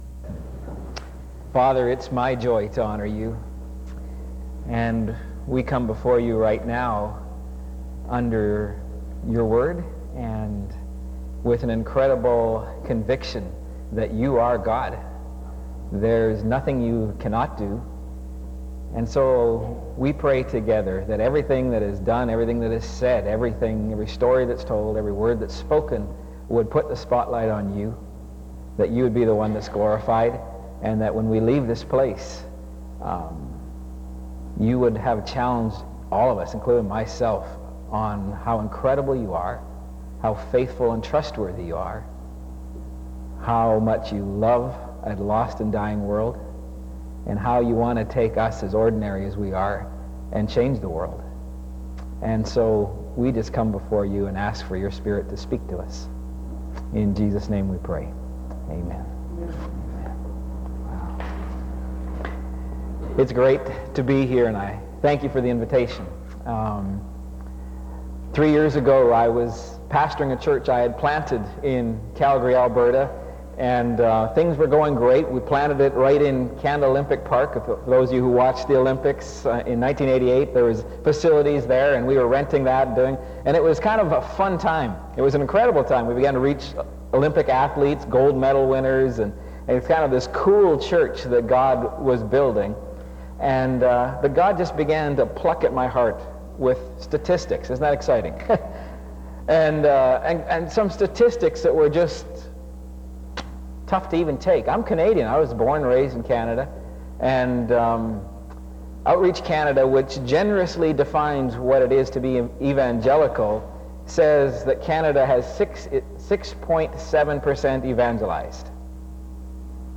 SEBTS Chapel and Special Event Recordings